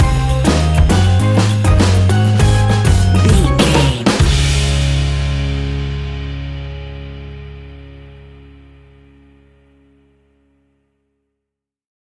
Ionian/Major
D♭
calypso
steelpan
drums
percussion
bass
brass
guitar